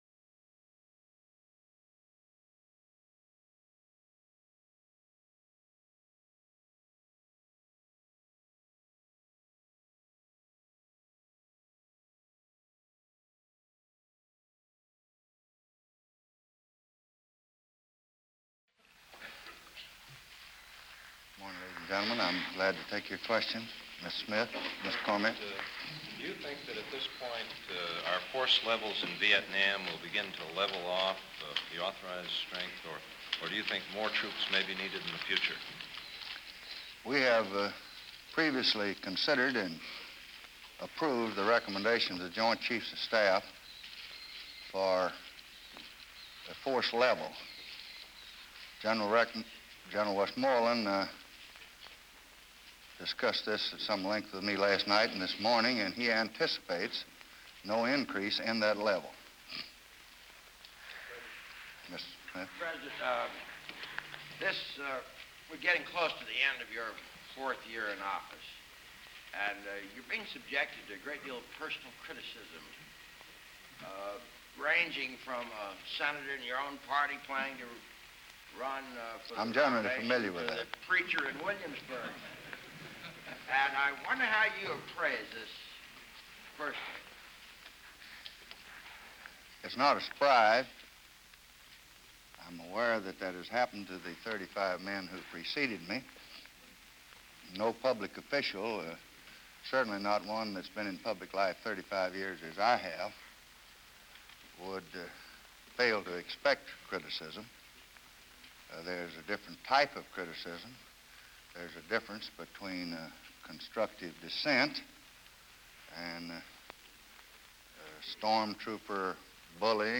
November 17, 1967: Press Conference